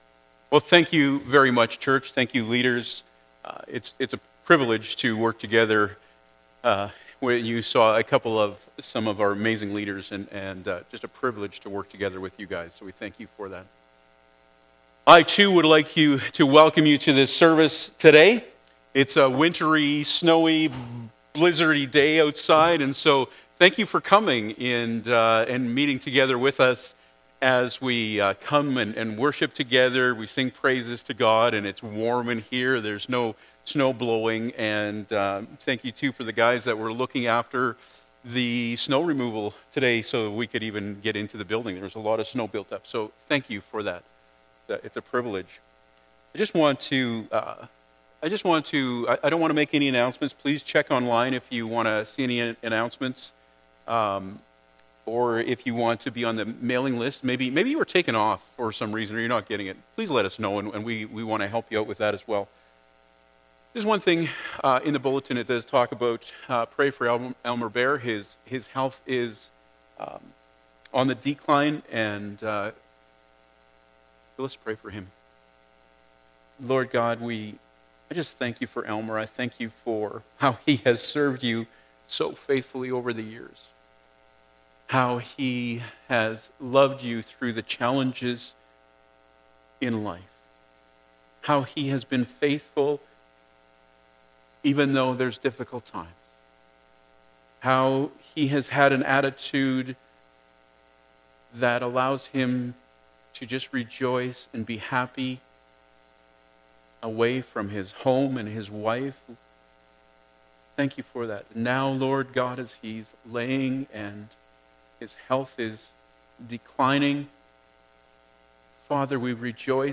Col 3:12-14 Service Type: Sunday Morning Bible Text